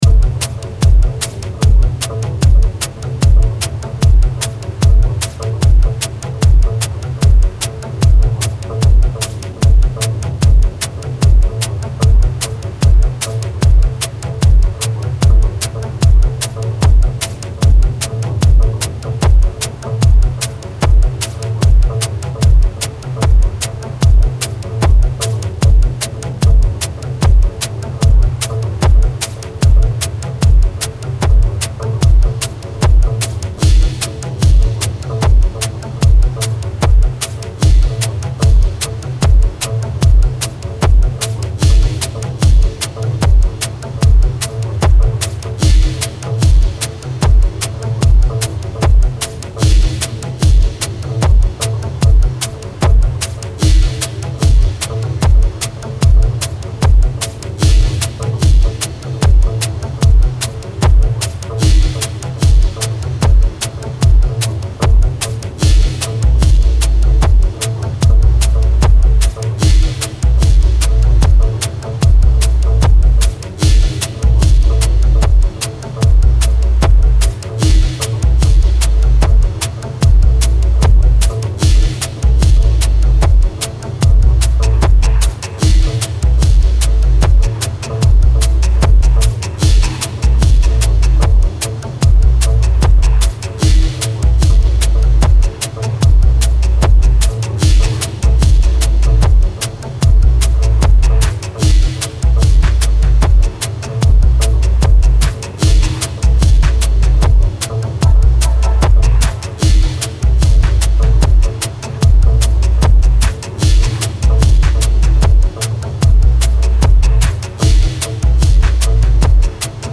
And yes, it is fucked up, but in a gentle way.
Braindance, people!
Best with headphones or very loud speakers.